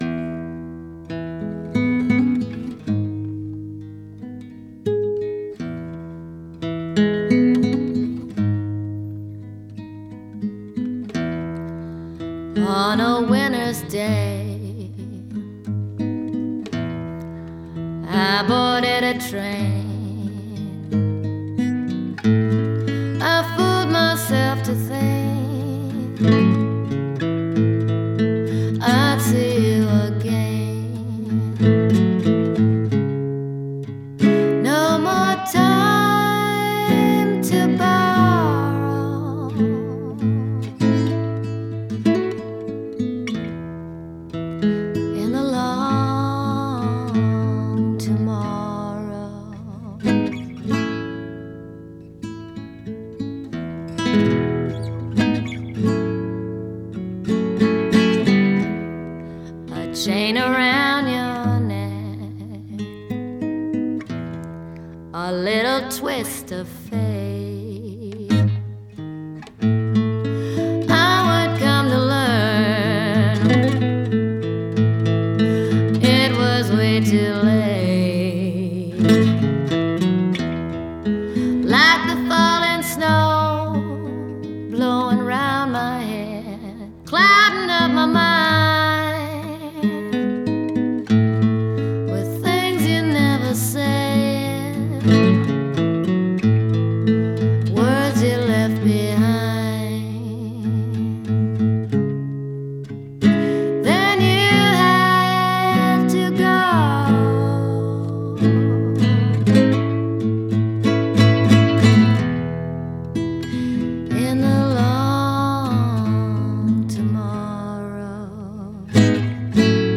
blues/roots